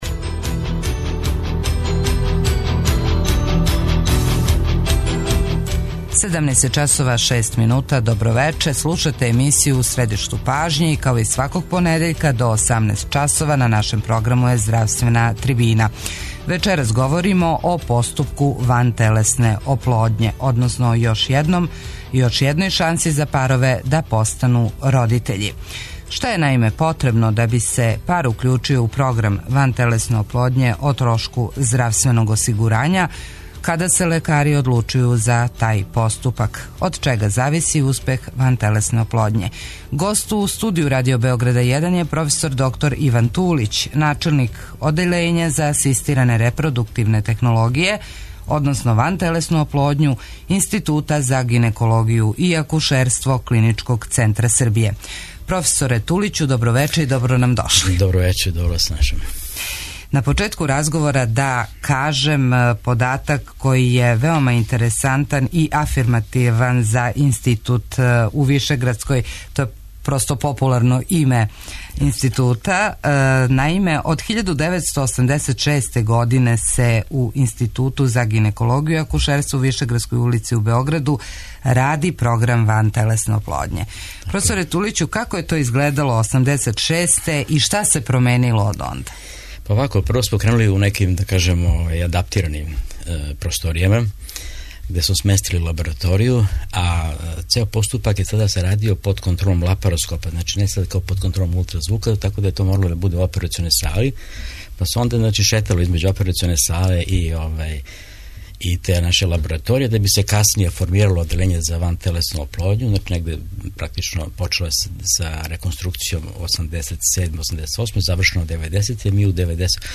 У здравственој трибини говоримо о поступку вантелесне оплодње.